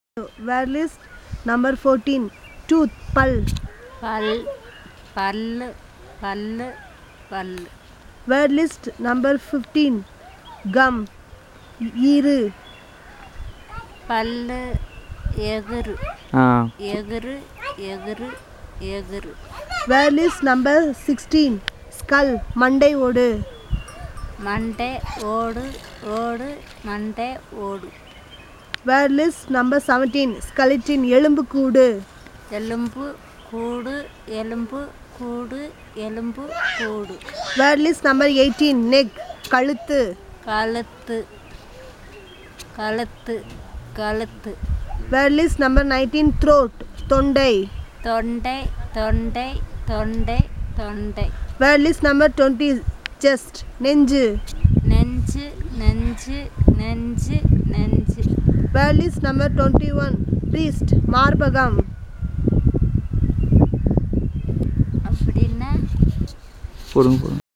NotesThis is an elicitation about human body parts, using the SPPEL Language Documentation Handbook.